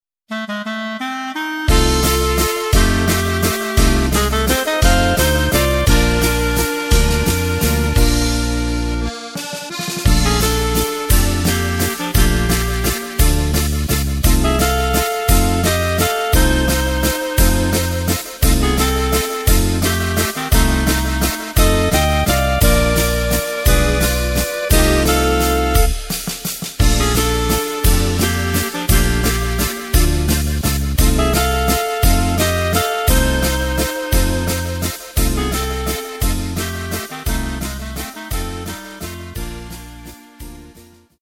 Takt:          3/4
Tempo:         172.00
Tonart:            Bb
Schöner Walzer im Oberkrainer-Sound!
Playback mp3 Mit Drums